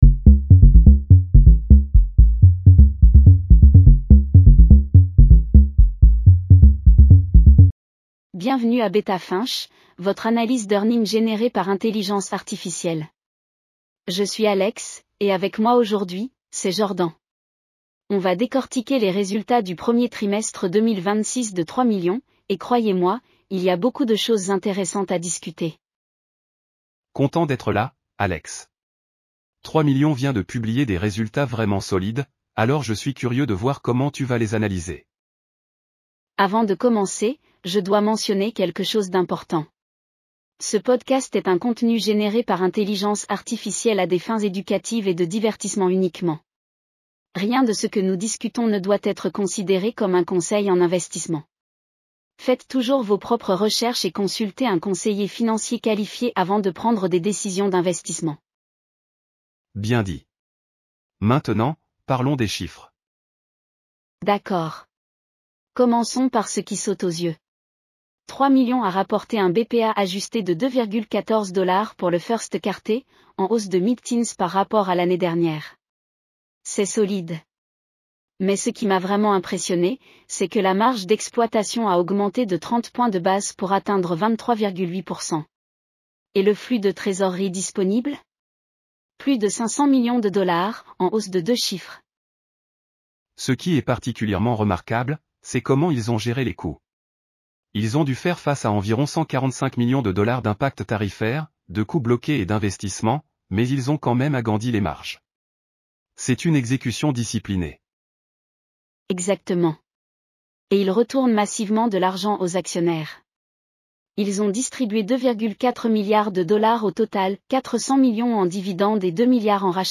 Bienvenue à Beta Finch, votre analyse d'earnings générée par intelligence artificielle.